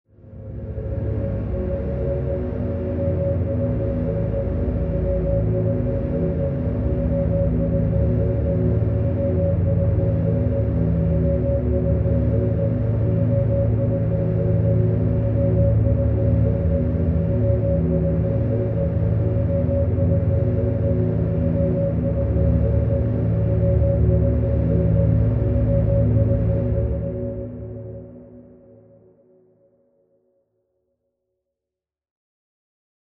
creepy.mp3